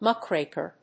/ˈmʌˌkrekɝ(米国英語), ˈmʌˌkreɪkɜ:(英国英語)/
アクセント・音節múck・ràk・er